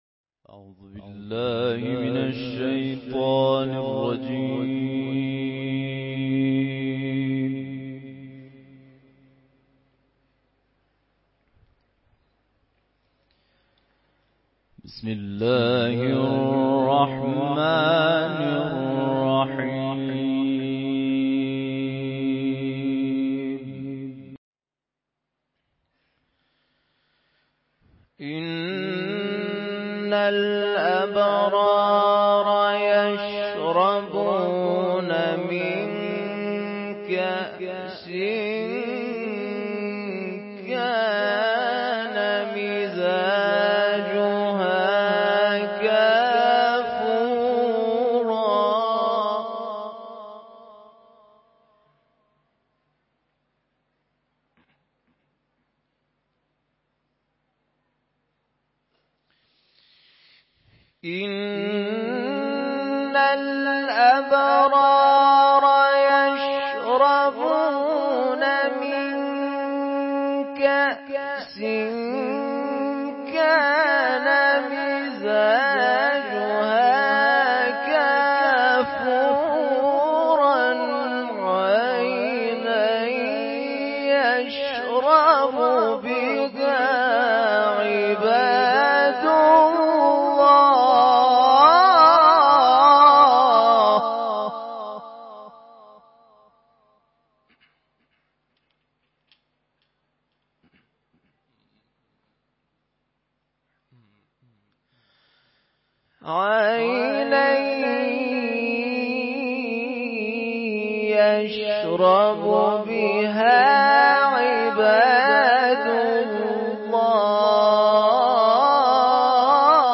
تلاوت قرآن کریم
شب ا محرم 96 شب اول محرم96 اشتراک برای ارسال نظر وارد شوید و یا ثبت نام کنید .